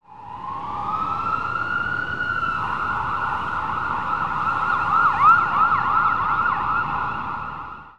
Martinhorn
Als Folgetonhorn werden generell akustische Einrichtungen an Fahrzeugen bezeichnet, die nacheinander mehrere Töne verschiedener Grundfrequenzen abgeben.
Üblicherweise werden die Tonfolgen wie z.B. a'–d", cis'–gis' cis–e–a verwendet.
horn